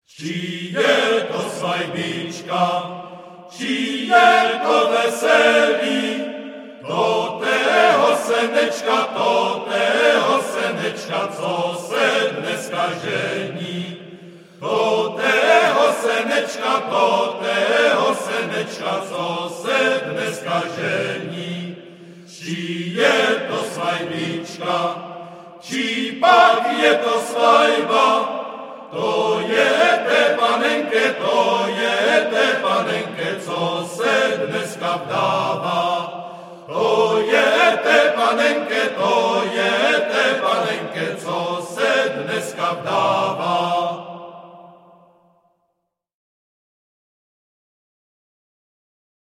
Žánr: World music/Ethno/Folk
písní a capella